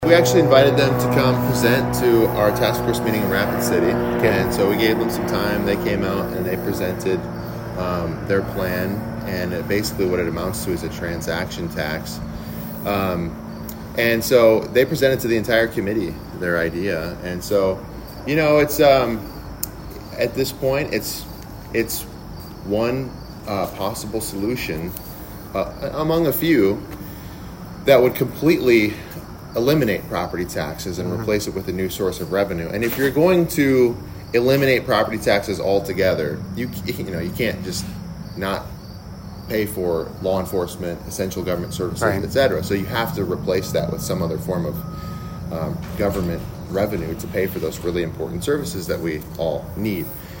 HubCityRadio had an opportunity to do an interview with him to address several different topics.